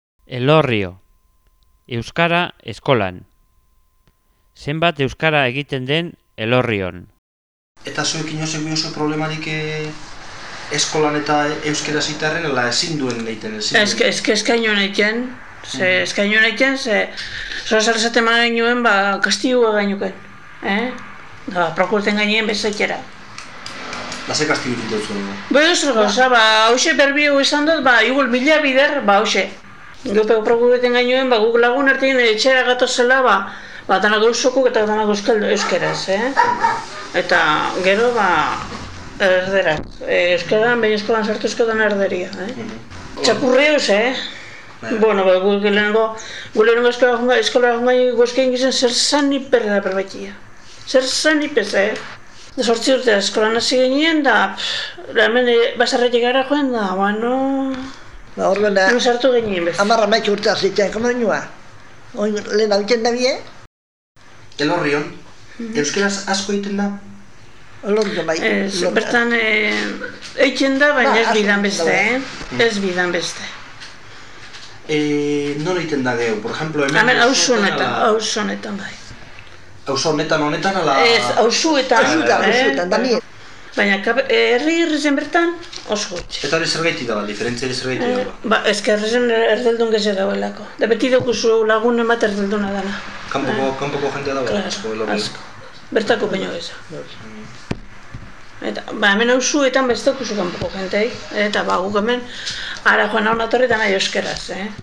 Elorrio.mp3